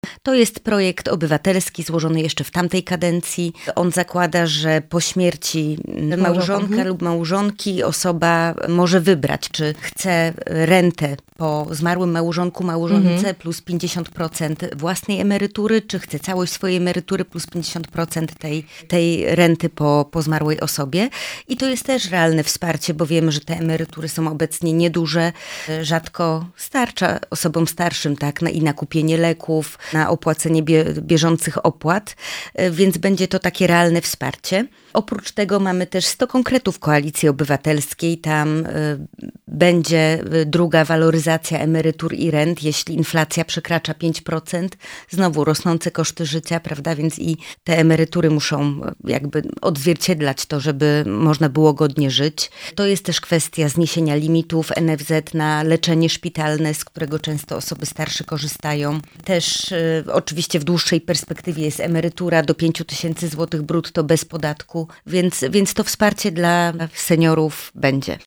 -To jeden z zapisów umowy koalicyjnej, mówi poseł na Sejm Małgorzata Tracz z partii Zieloni (KO).